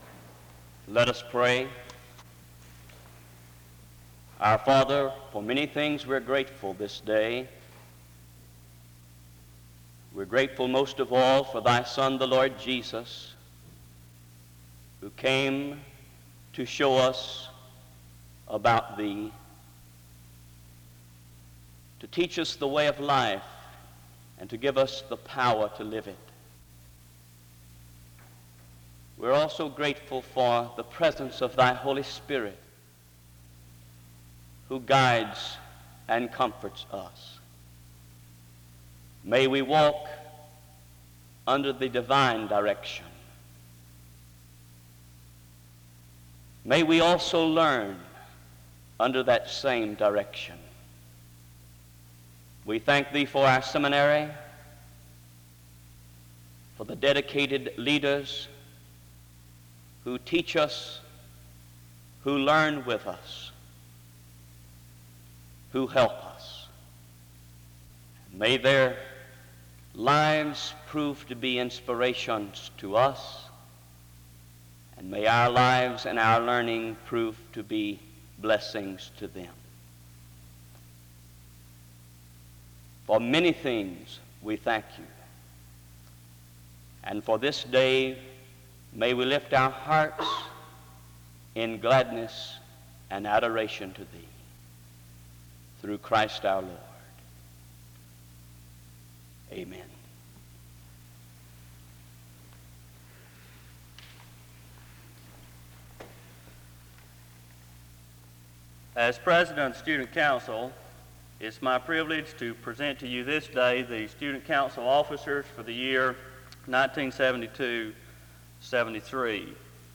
Location Wake Forest (N.C.)
SEBTS Chapel and Special Event Recordings